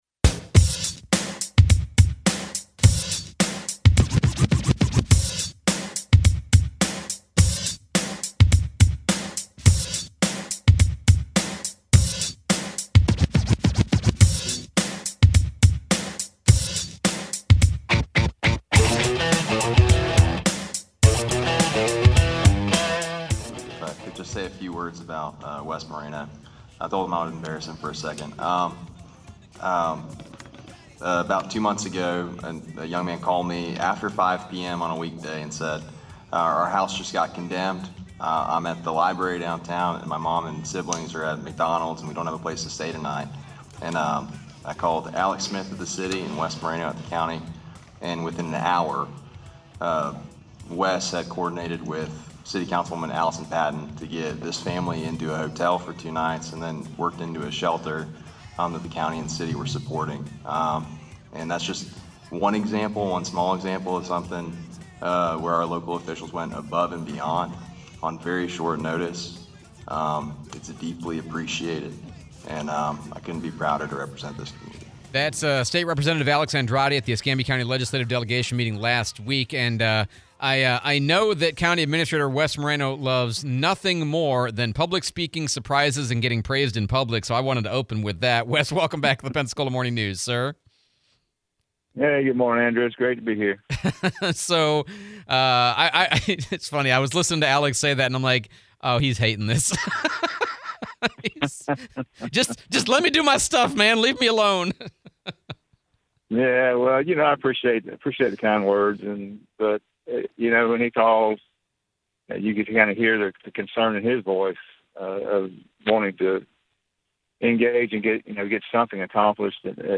11/02/2023 - Interview with Wes Moreno - Escambia County Administrator